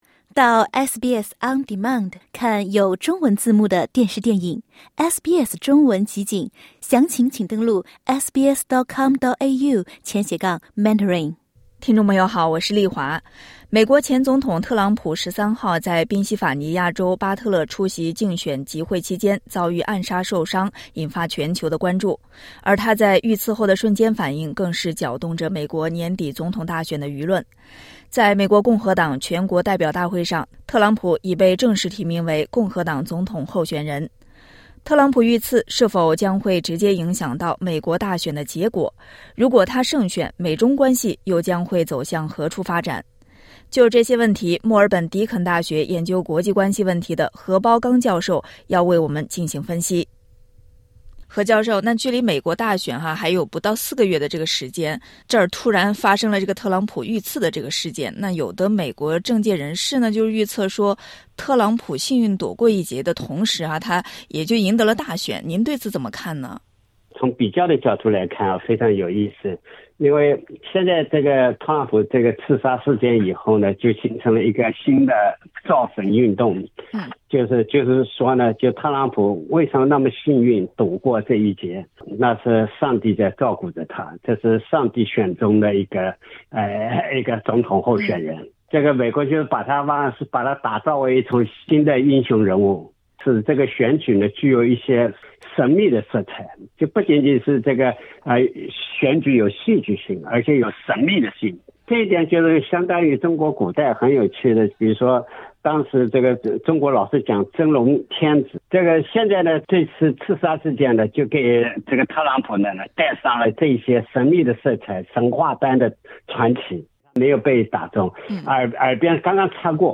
点击下方音频，收听完整采访： LISTEN TO 【观点】特朗普遇刺会如何影响美国大选？